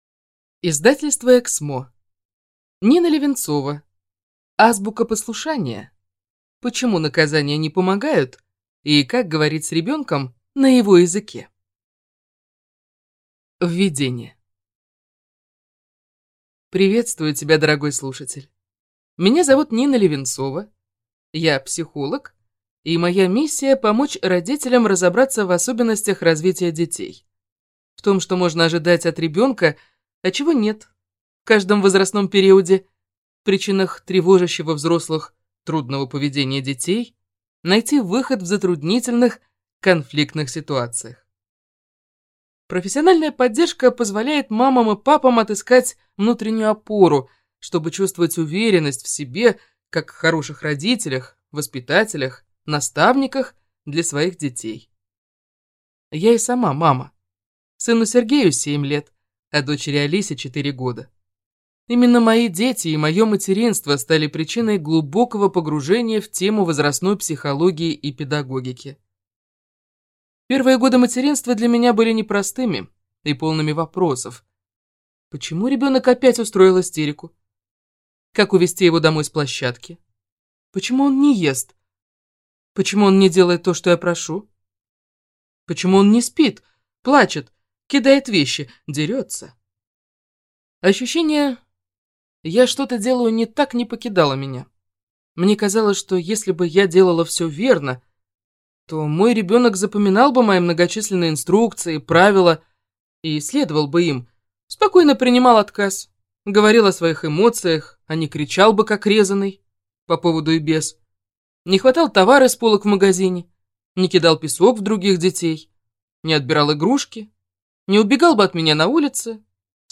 Аудиокнига Азбука послушания. Почему наказания не помогают и как говорить с ребенком на его языке | Библиотека аудиокниг